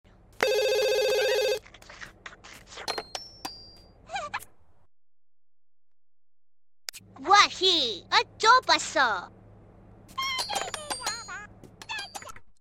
Answering the Landline Telephone 😀 sound effects free download